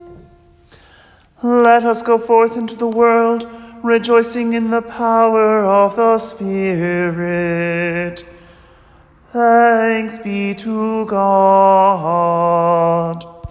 Below you will find a draft bulletin with audio files to listen and practice singing along as St. Hilda St. Patrick observes a principal feast with considerably more chanting.